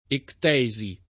[ ekt $@ i ]